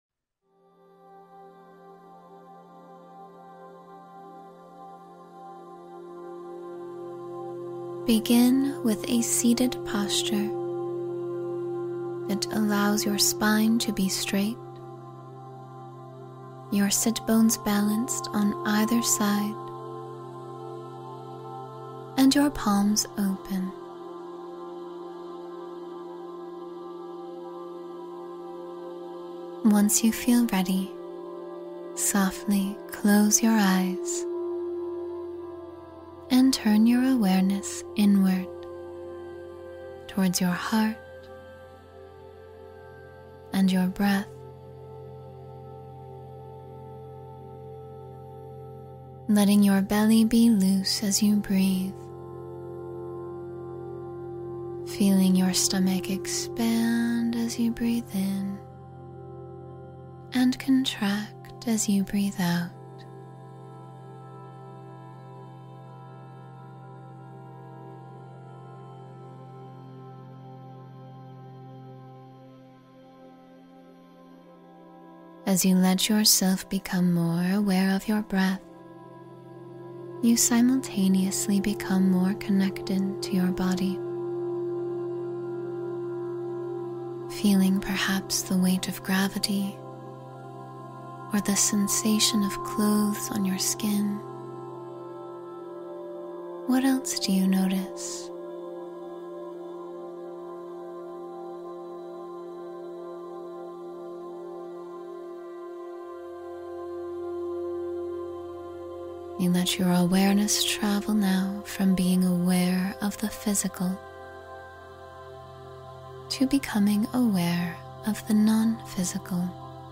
Release Old Karma and Begin Anew — Guided Meditation for Personal Transformation